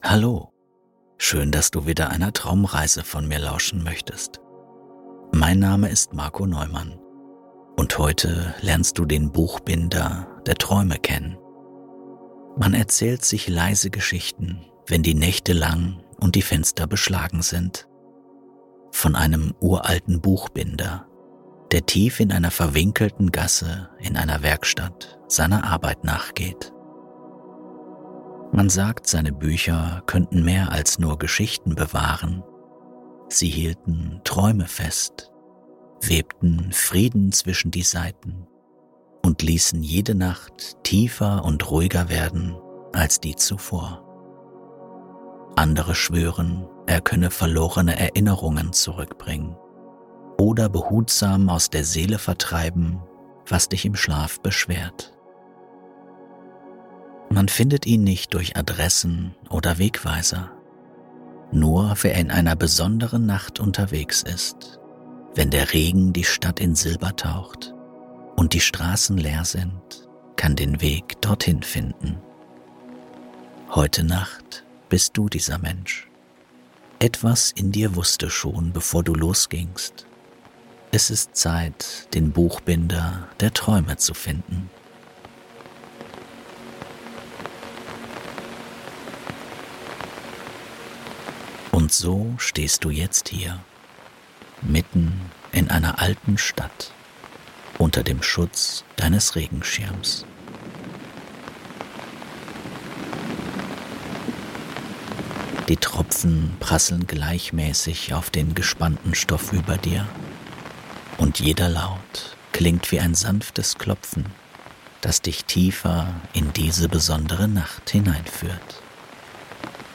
Diese eine Traumreise lässt dich SOFORT einschlafen! Der mystische Buchbinder + Regen